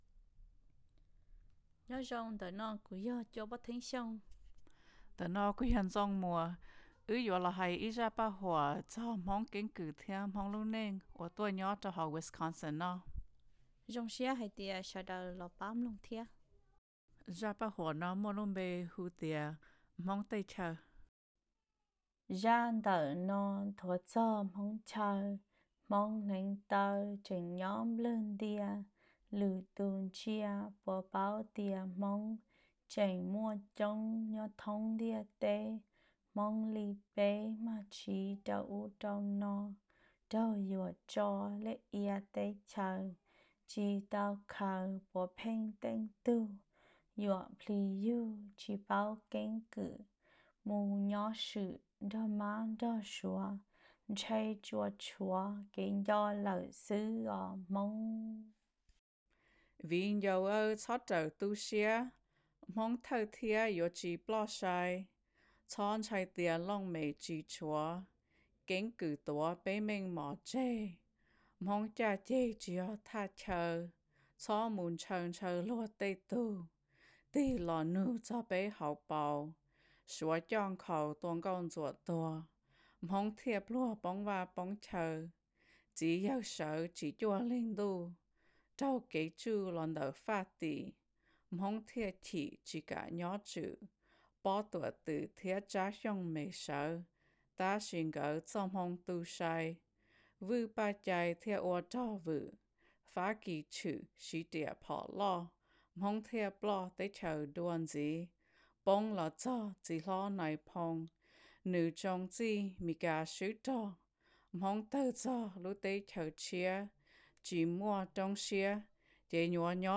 This duet begins with a HMoob female voice expressing her deep yearning to know HMoob history and her feelings of nostalgia for home/land.